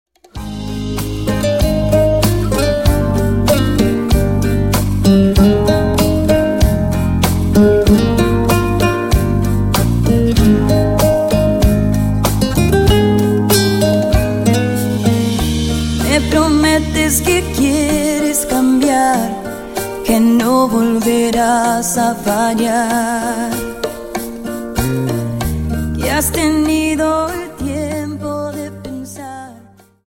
Rumba 25 Song